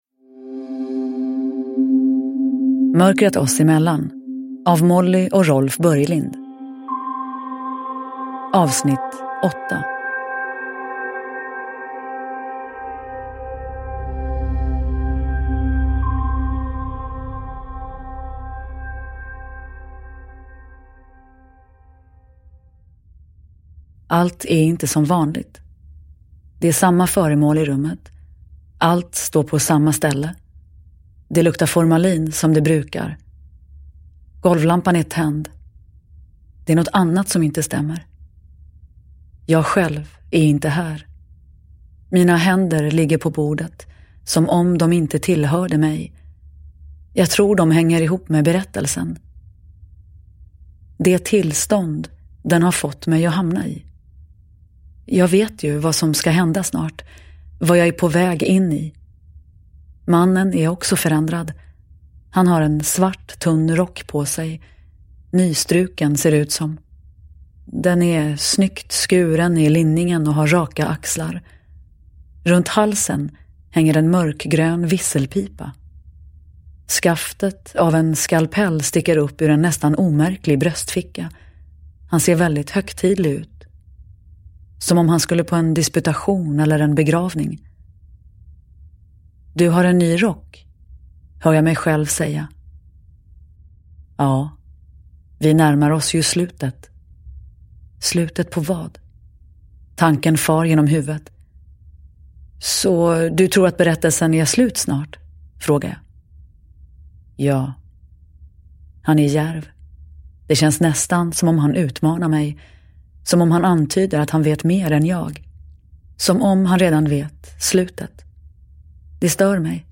Mörkret oss emellan. 8 – Ljudbok
Uppläsare: Nina Zanjani